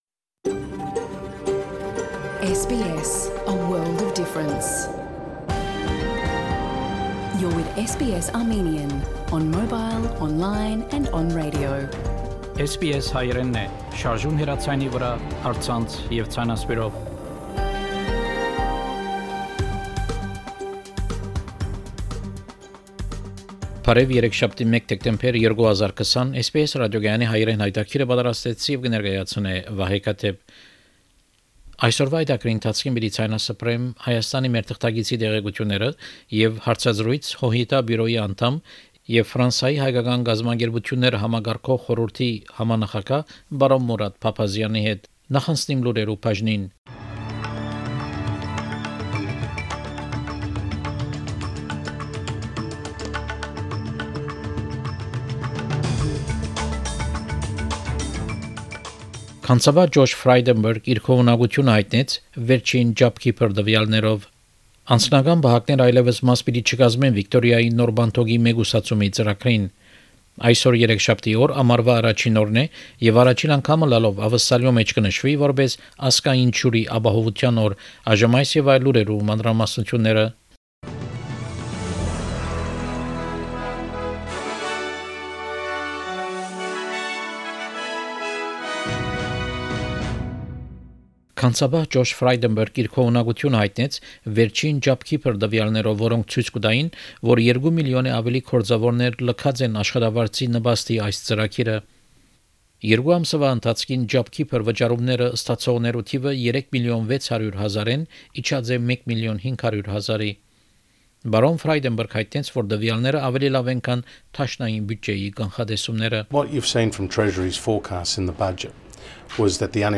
SBS Armenian news bulletin – 1 December 2020
SBS Armenian news bulletin from 1 December 2020 program.